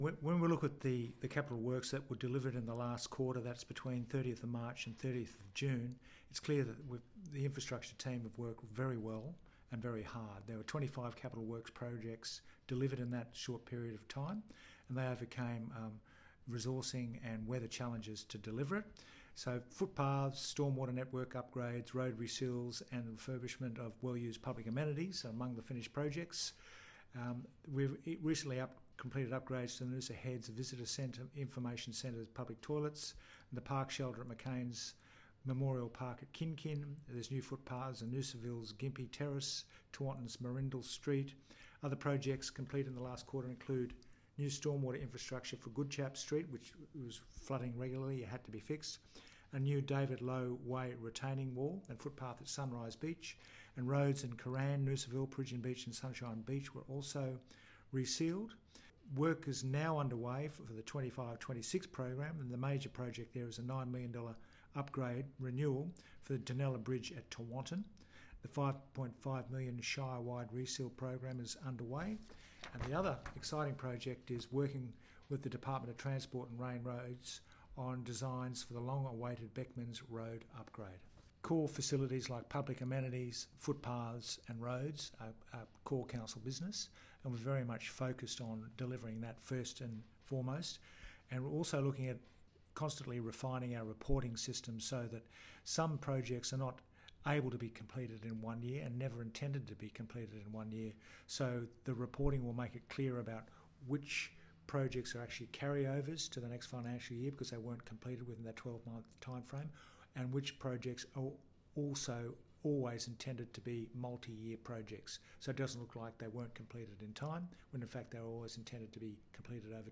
Mayor Frank Wilkie discusses completion of the 2024-25 Capital Works Program: